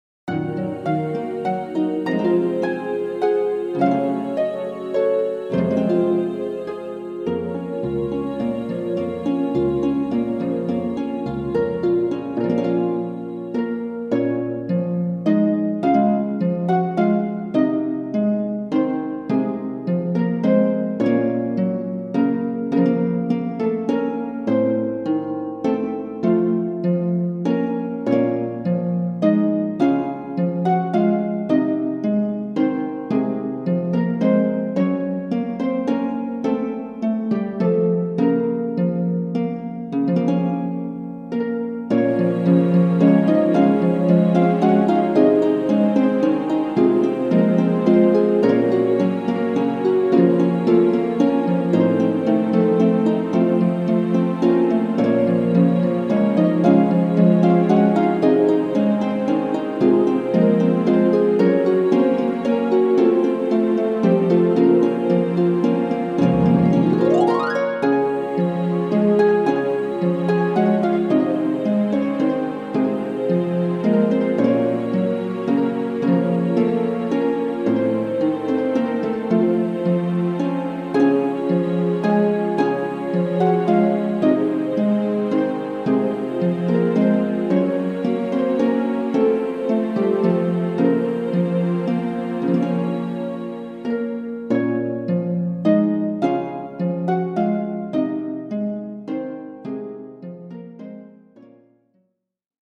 (音量注意)
アイリッシュハープのカルテット編成でやってます。
イントロはオリジナル。